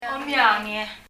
–ange の部分は、ガ行の音を持たないパラオ語ならではの音韻変化、といえるでしょう。